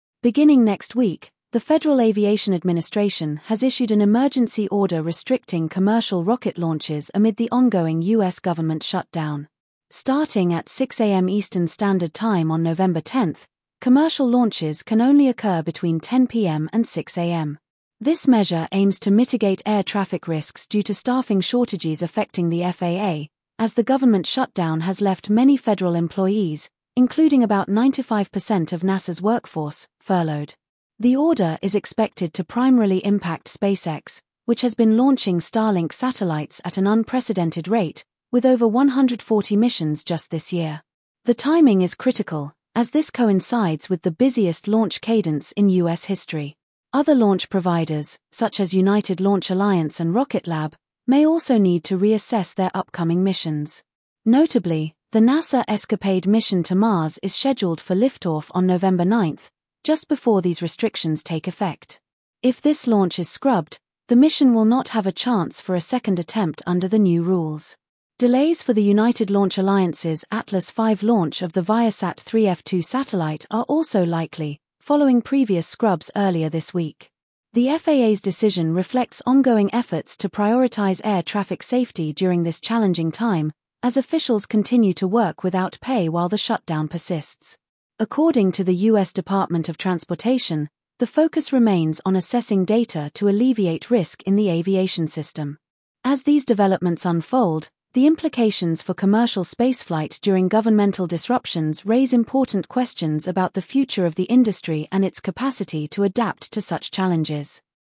FAA Restricts Commercial Rocket Launches Amid Government Shutdown - Daily Audio News Transcript